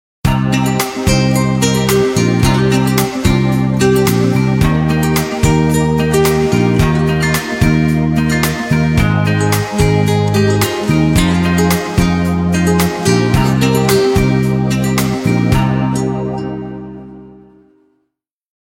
• Качество: 128, Stereo
гитара
без слов
инструментальные